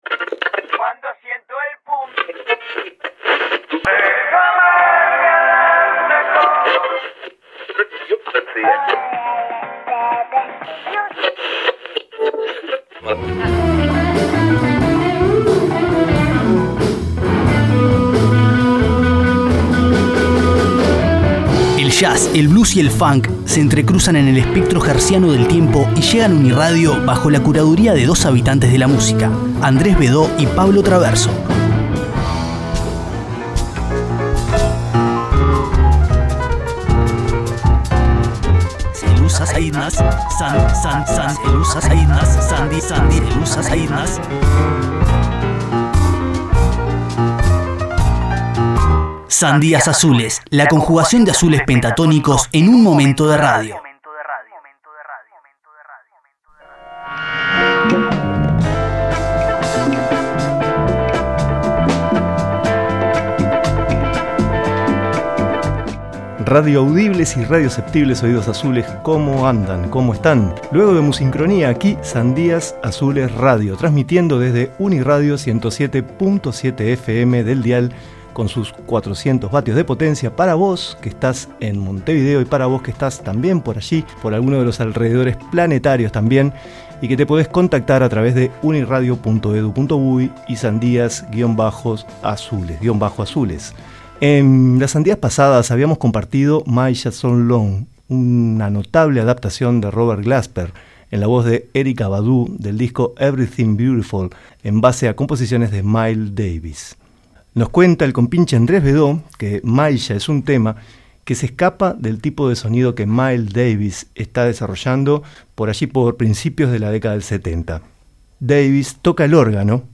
Guitarrista y cantante. Su técnica de toque rural dejó la enseñanza para futuros músicos del country billy / country blues y el rock.